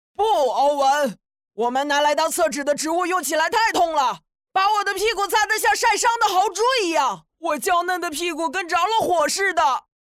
动漫语音
杜德利： 13岁左右，厚实洪亮的正太音色，声优用偏快的语速和夸张且张扬表演方式，成功演绎了一个身材矮小却口若悬河，自负且有些小聪明，自诩物理化学天才，并担任着呆宝团队的领袖形象。